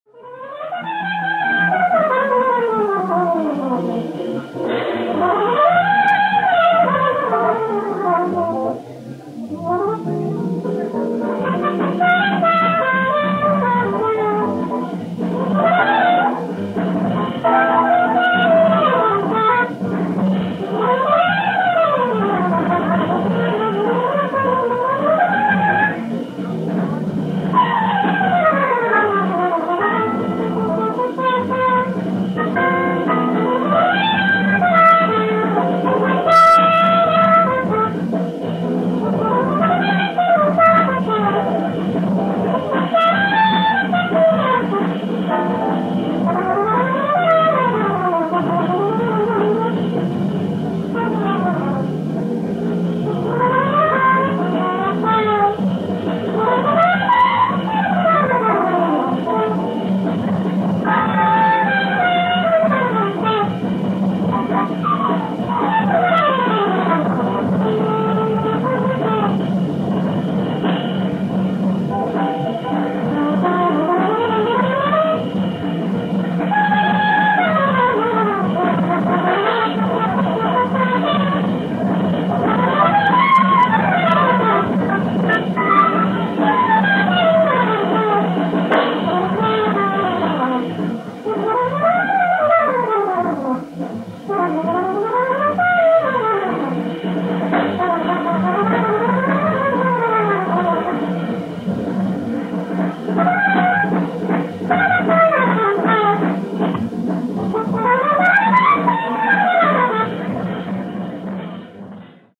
Live At Jazz Workshop, Boston, MA December 5th-8th, 1968